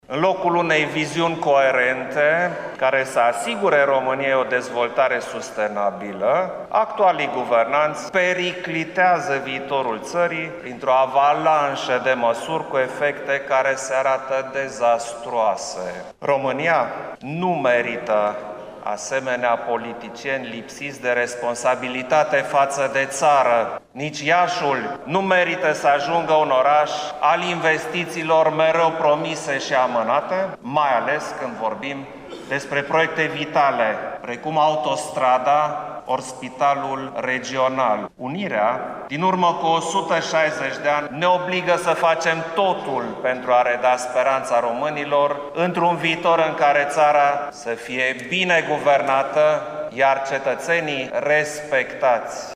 În loc de a adopta măsuri responsabile faţă de ţară şi cetăţeni „guvernanţii au preferat o hărmălaie populistă şi o jalnică ţopăială legislativă şi administrativă” a mai spus în cuvântul său, preşedintele României.